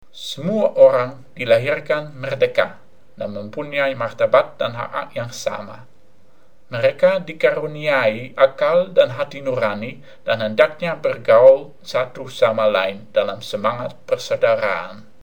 The speaker, conspicuously, has a uvular /r/ which lets me doubt, if he is a native speaker.